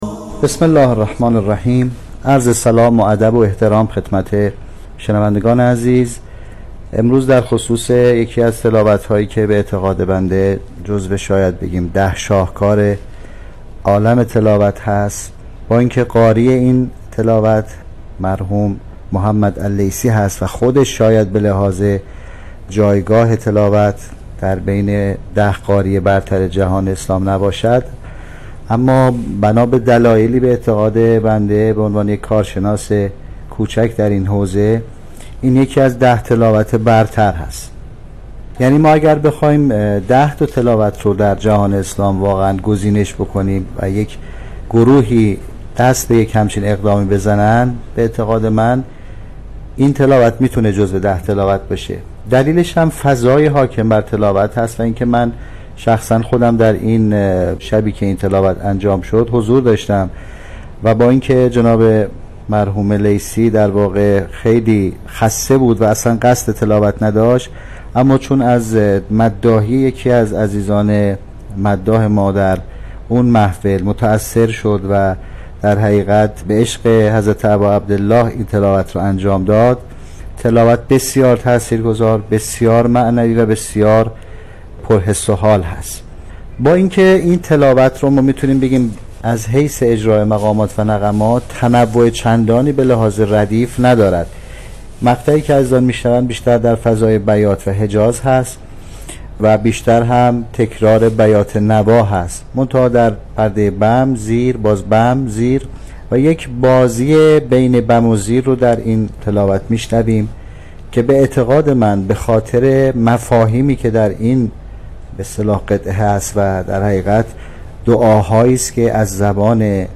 یادآور می‌شود، این تحلیل در برنامه «اکسیر» به تاریخ اول شهریور ۱۳۹۷ ساعت ۱۶:۳۰ تا ۱۸ از شبکه رادیویی قرآن پخش شد.